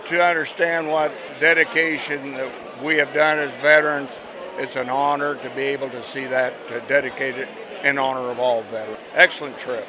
He spoke with KMAN about the experience.